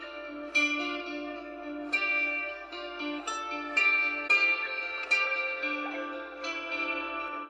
Звук гуслей Пример звучания гуслей